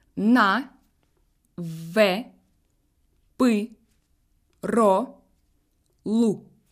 1.Si la consonne est suivie d'une voyelle de première série :[1] (а, э, ы, о, у), on dit qu'elle est dure.